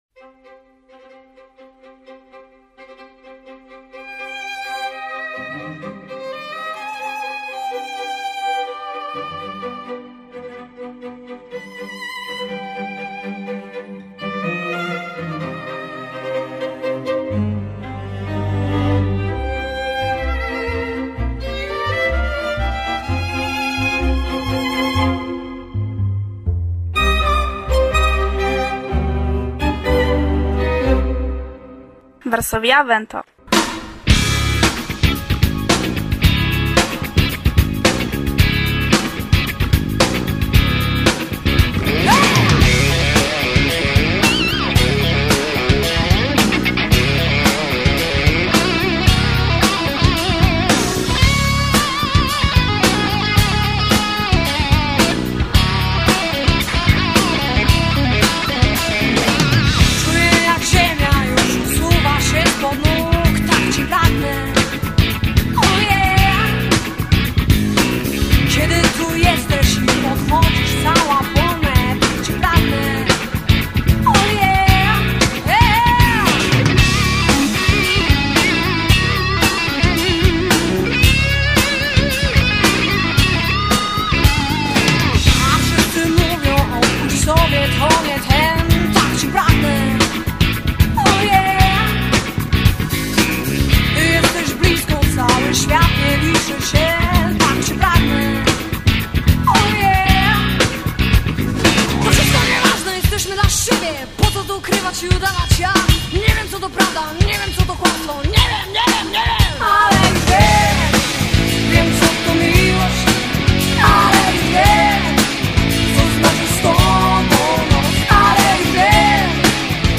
Zamenhofaj Tagoj en Bjalistoko